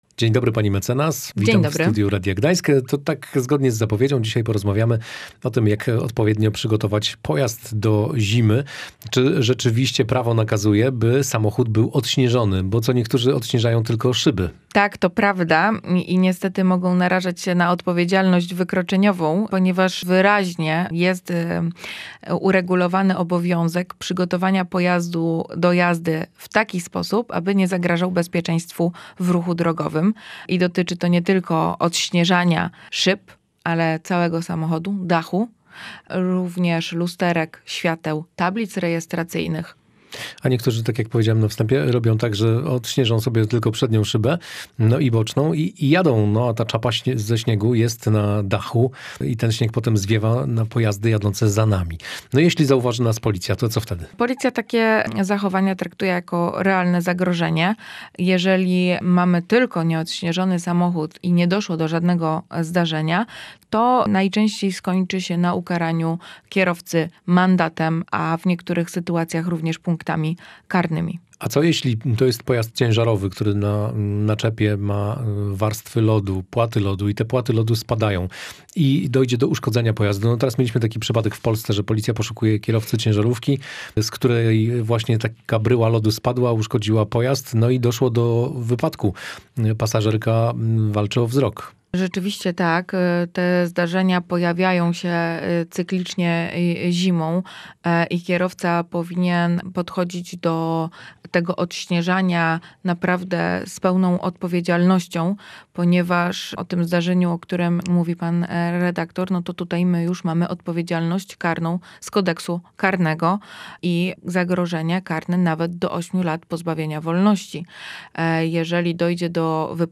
W audycji „Prawo na co dzień” rozmawialiśmy o zimowym utrzymaniu samochodów. Kto i kiedy ma obowiązek usuwania oblodzenia z maski, naczepy pojazdu ciężarowego i czy wolno odśnieżać auto przy uruchomionym silniku? No i co z tymi oponami zimowymi: są obowiązkowe czy nie?